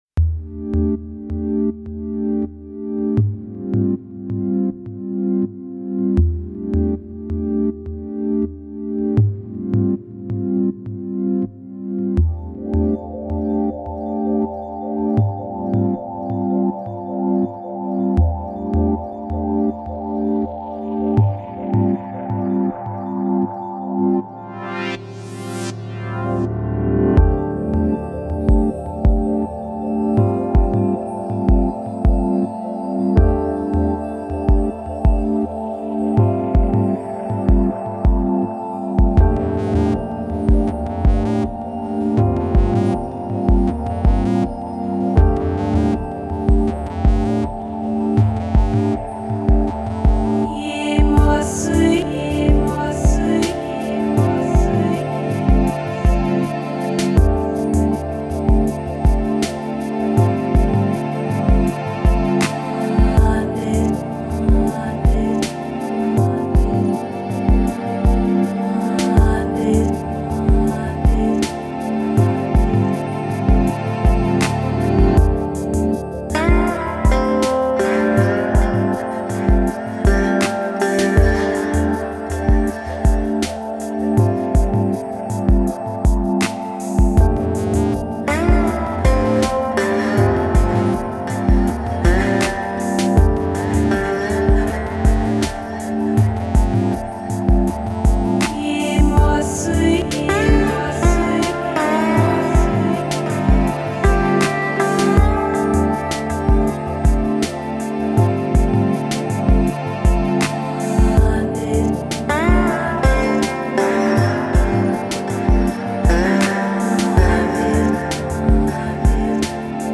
Genre: Downtempo, Chillout.